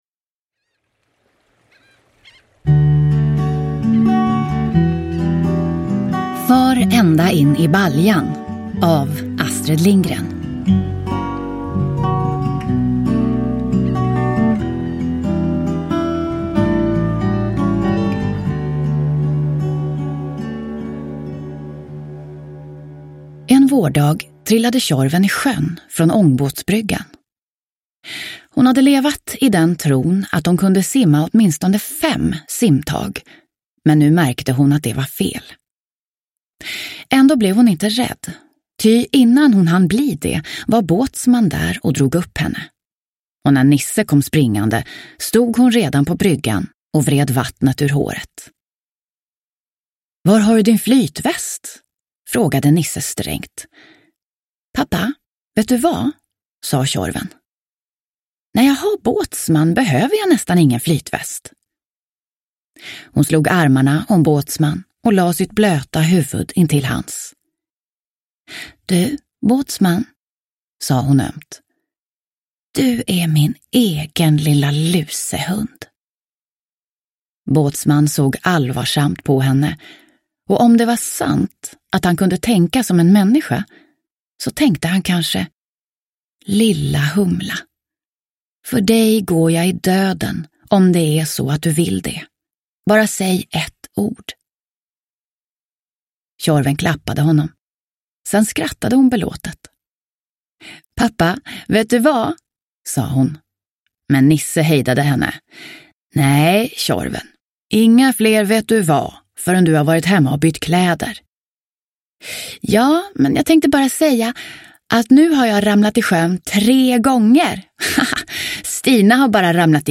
Far ända in i baljan – Ljudbok – Laddas ner